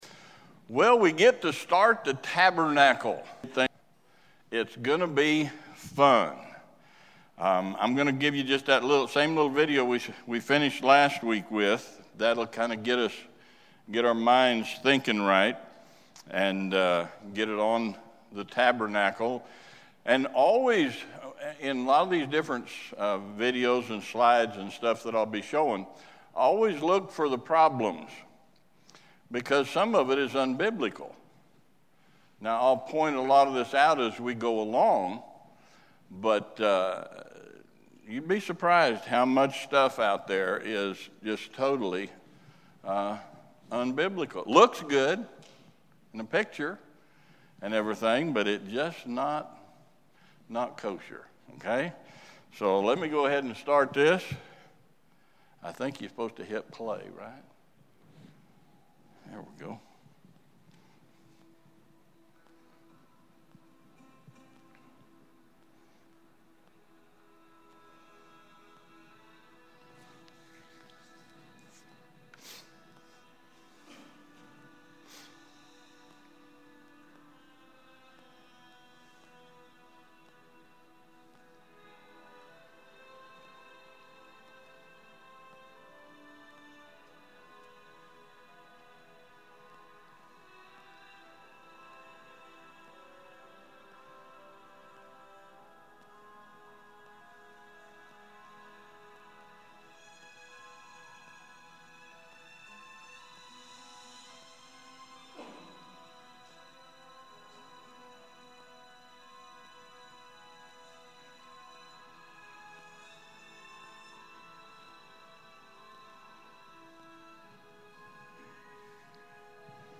The Tabernacle Part 10 Sunday School